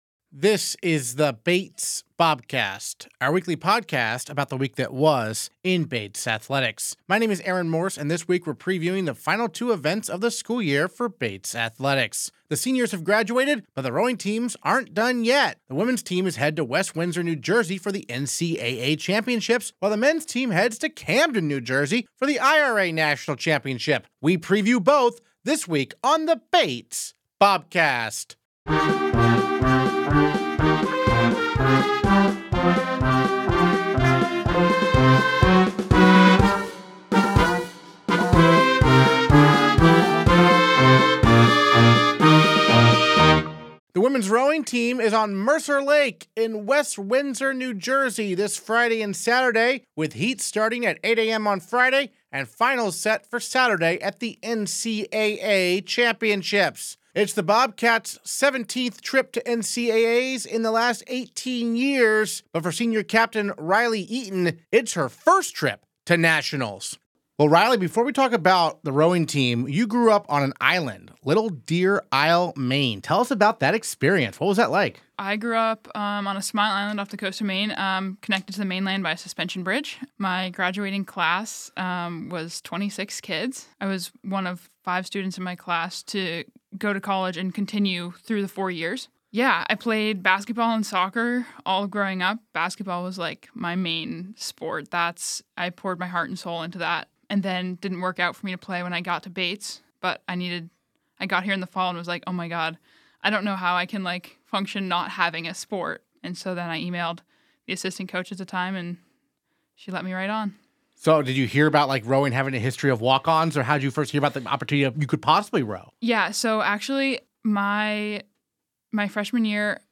Interviews this episode: